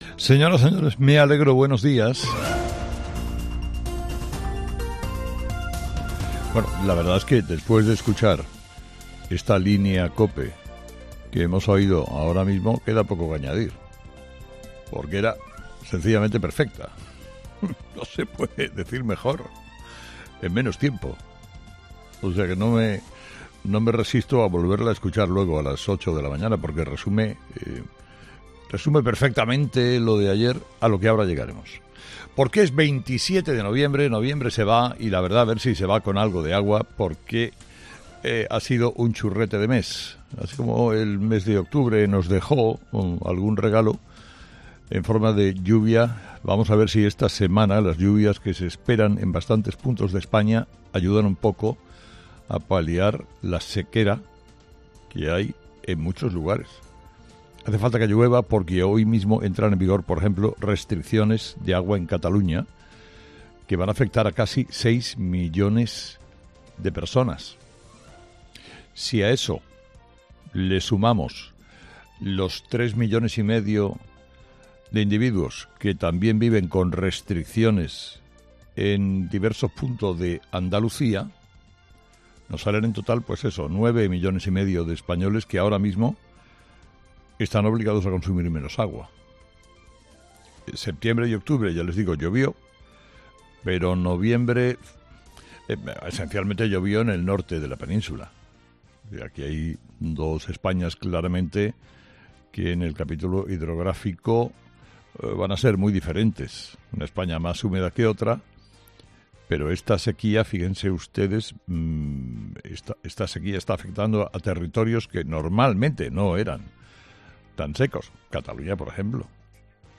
Carlos Herrera, director y presentador de 'Herrera en COPE', comienza el programa de este lunes analizando las principales claves de la jornada que pasan, entre otras cosas, por Pedro Sánchez y la España más cohesionada con la ley de amnistía.